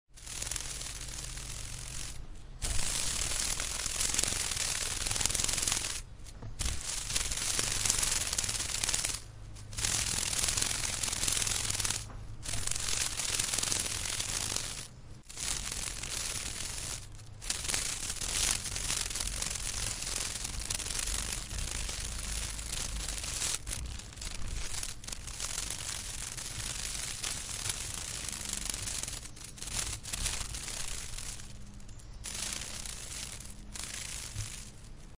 Здесь вы найдёте разные варианты записей: от нежного шелеста до интенсивного жужжания во время быстрого полёта.
Звук полета стрекозы